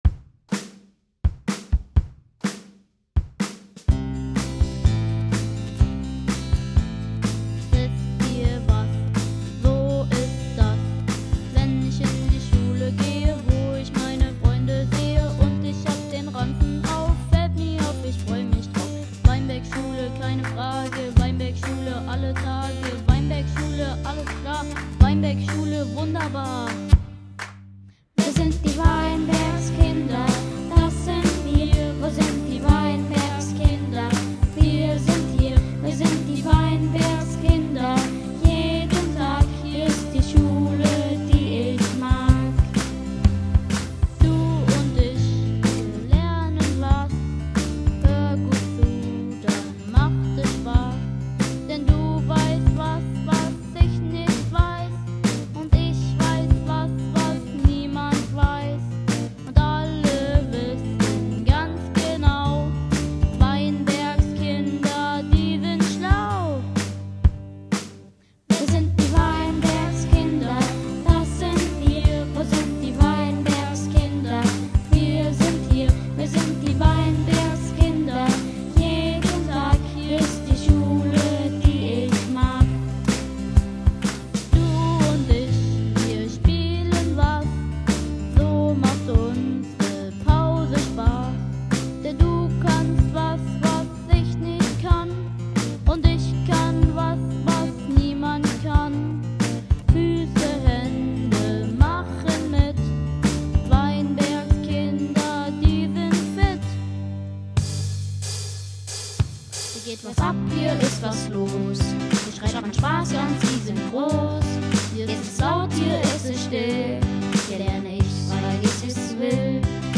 Unser Schullied
Lied - Wir sind die Weinbergskinder - mit Gesang
Audio_mit_Gesang+.mp4